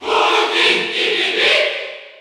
Category: Crowd cheers (SSBU) You cannot overwrite this file.
Ludwig_Cheer_French_PAL_SSBU.ogg.mp3